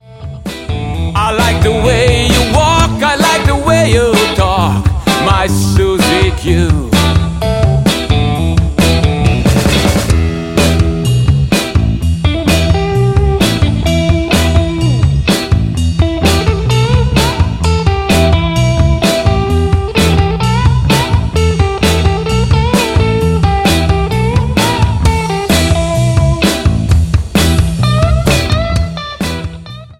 Gesang + Keyboard
Gitarre + Gesang
Drums + Percussion
Bass + Gesang